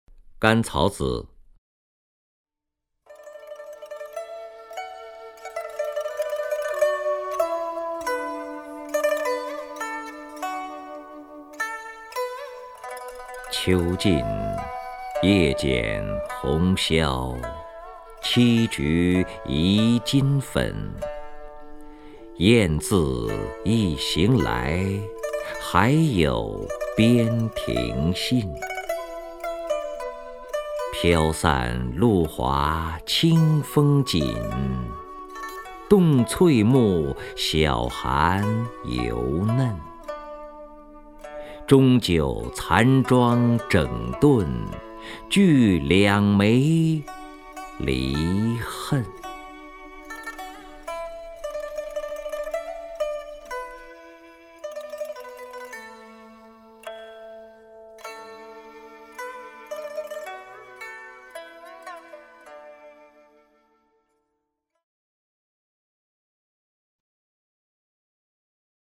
首页 视听 名家朗诵欣赏 任志宏
任志宏朗诵：《甘草子·秋尽》(（北宋）柳永)